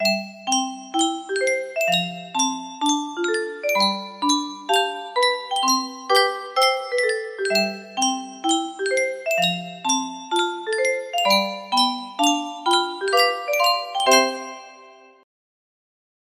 Yunsheng Music Box - Little Brown Jug 1716 music box melody
Full range 60